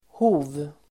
Uttal: [ho:v]